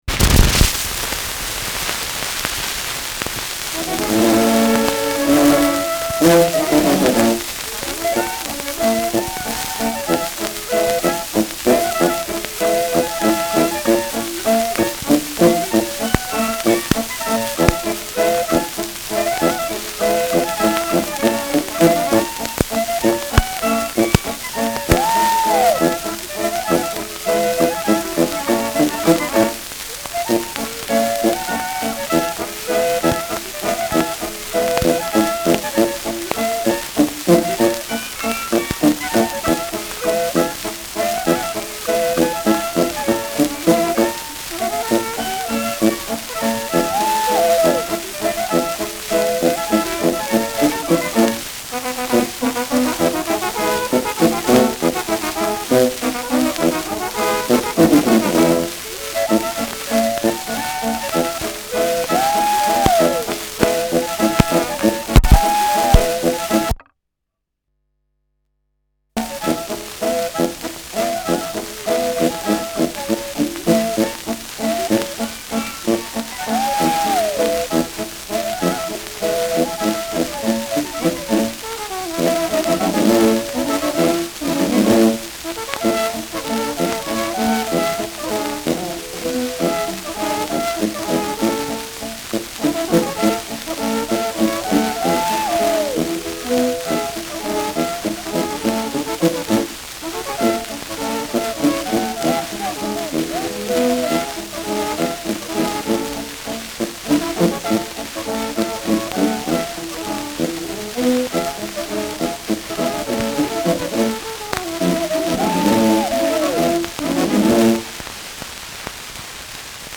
Schellackplatte
Tonrille: Kerbe 1 / 6 / 10 -11 Uhr Stark : Kratzer Durchgehend Leicht
Abgespielt : Tonarm springt durch tiefe Kerbe zurück, Aufnahme weiter hinten fortgesetzt
Kapelle Pokorny, Bischofshofen (Interpretation)